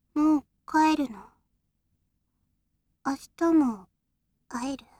システムボイス　桜花